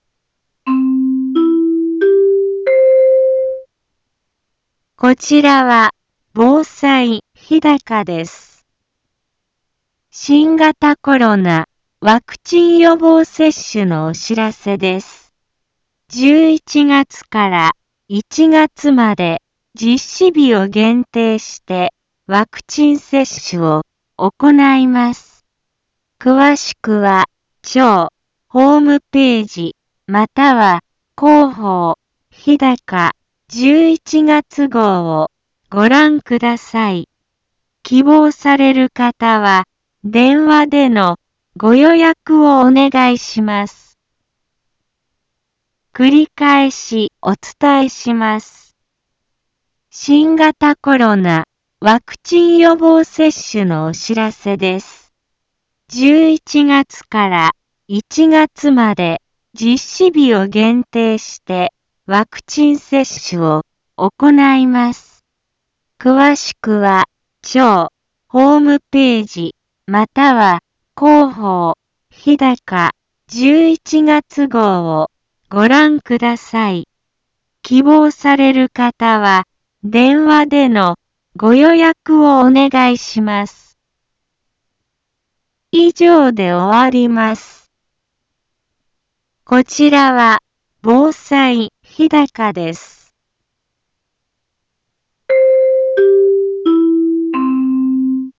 Back Home 一般放送情報 音声放送 再生 一般放送情報 登録日時：2021-11-01 10:03:29 タイトル：新型コロナワクチン予防接種のお知らせ インフォメーション：こちらは防災日高です。